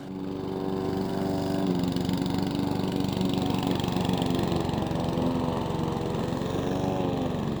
mower_loop.wav